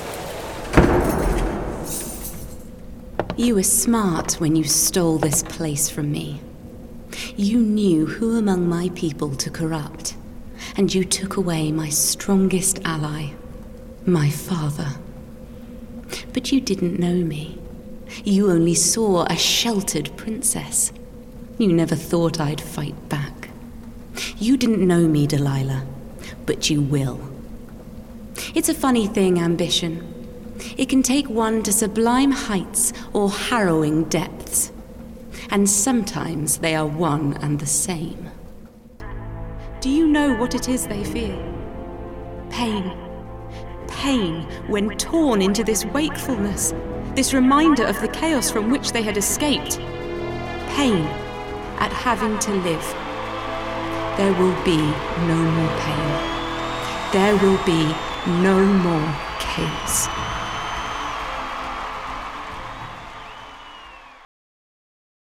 Her voice is assured, smooth and engaging.
Female / 20s, 30s / English / Southern Showreel http